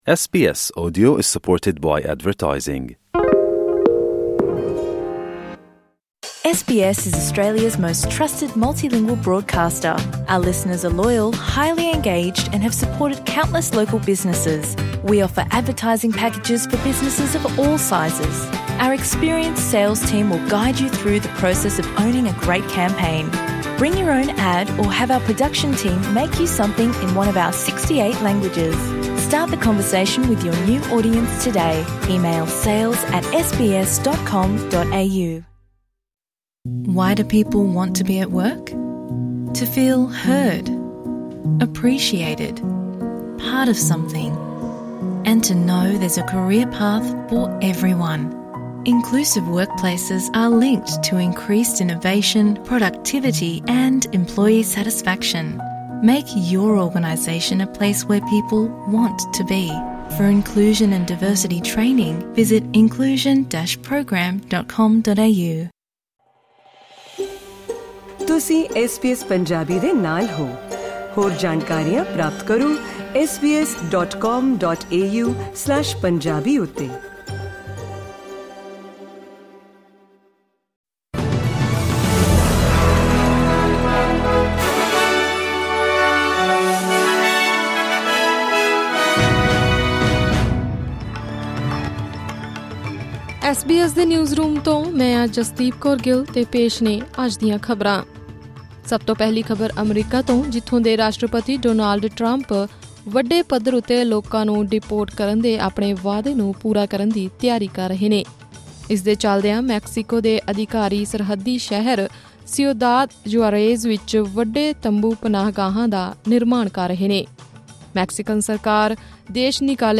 ਖ਼ਬਰਨਾਮਾ: ਡੋਨਾਲਡ ਟਰੰਪ ਵੱਲੋਂ ਵੱਡੇ ਪੱਧਰ 'ਤੇ ਲੋਕਾਂ ਨੂੰ ਡਿਪੋਰਟ ਕਰਨ ਦੀ ਤਿਆਰੀ ਸ਼ੁਰੂ